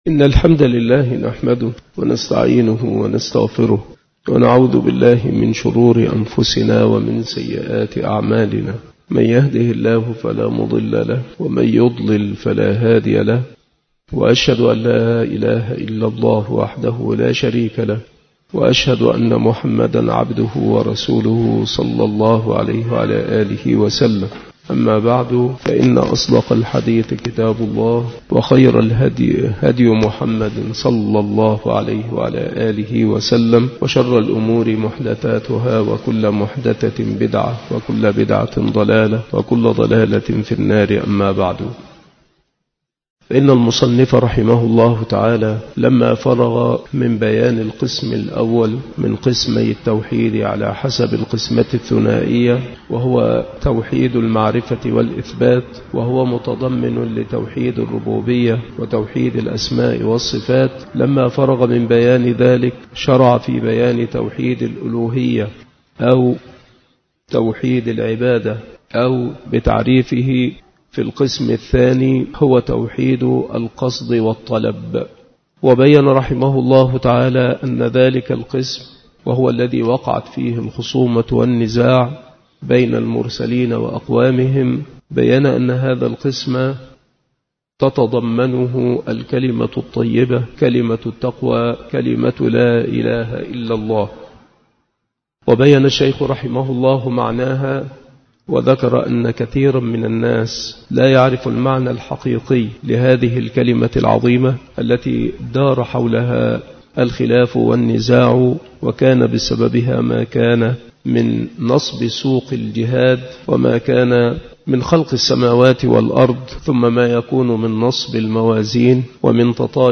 مكان إلقاء هذه المحاضرة بالمسجد الشرقي بسبك الأحد - أشمون - محافظة المنوفية - مصر عناصر المحاضرة : شروط شهادة أن لا إله إلا الله: الأول: العلم. الثاني: اليقين. الثالث: القبول. الرابع: الانقياد. الخامس: الصدق. السادس: الإخلاص. السابع: المحبة. الثامن: الكفر بكل ما يُعبد من دون الله. التاسع: النطق بها عند القدرة على ذلك.